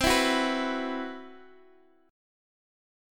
Esus2/C chord